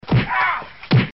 挨打声.mp3